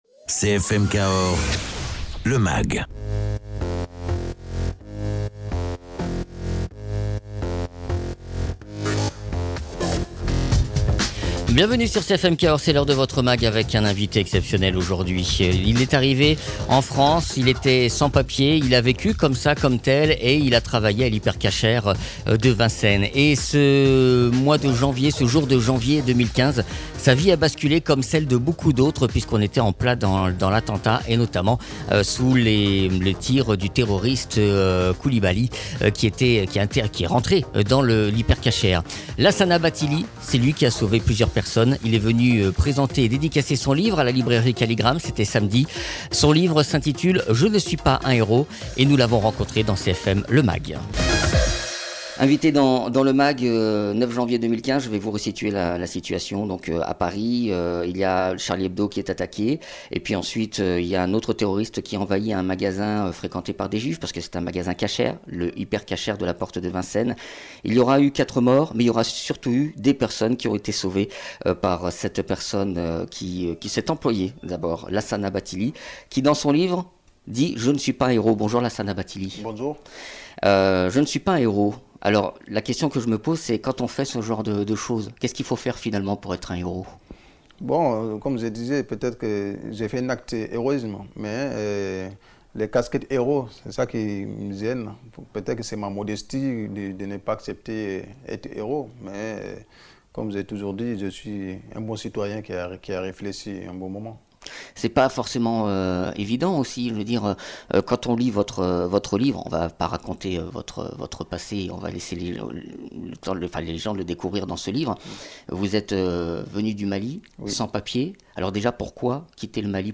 Invité(s) : Lassana Bathily, auteur.